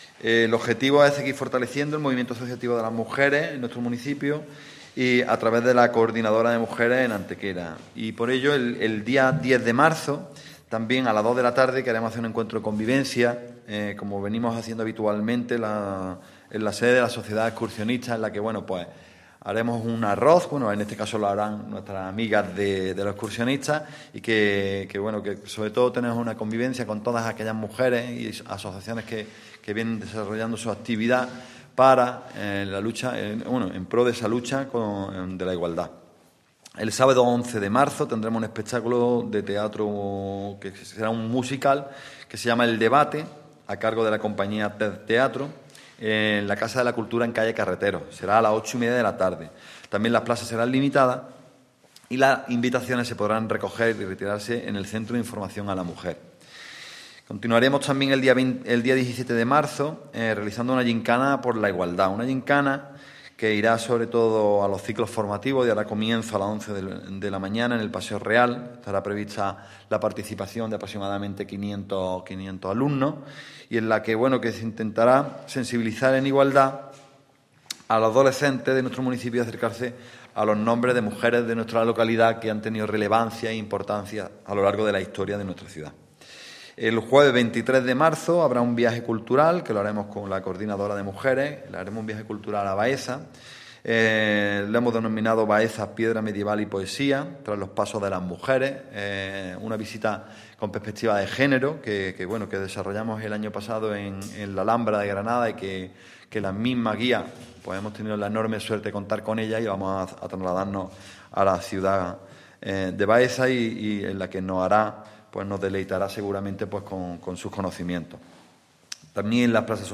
El teniente de alcalde delegado de Programas Sociales e Igualdad, Alberto Arana, ha presentado hoy en rueda de prensa el programa de actividades conmemorativo en nuestra ciudad del Día Internacional de la Mujer.
Cortes de voz